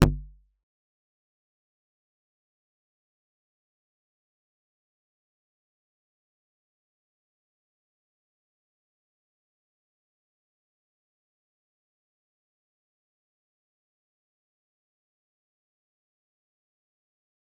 G_Kalimba-C1-mf.wav